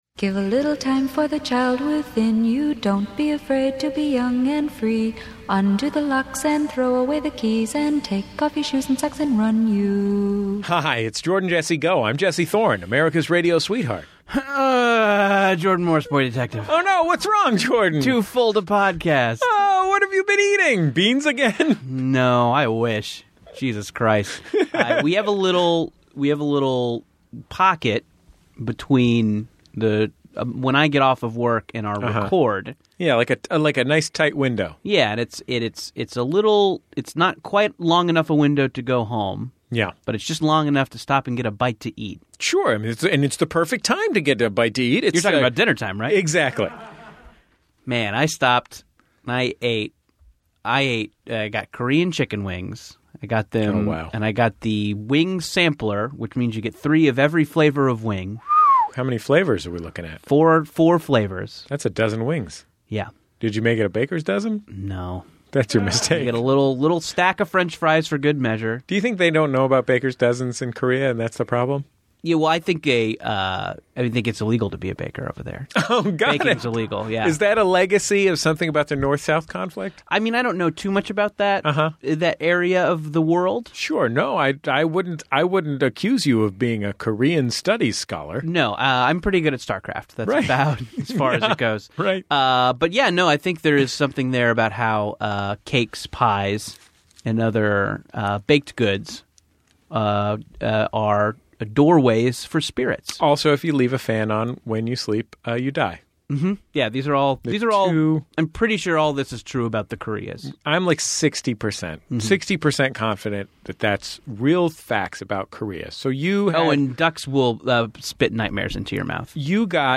Society & Culture, Comedy, Tv & Film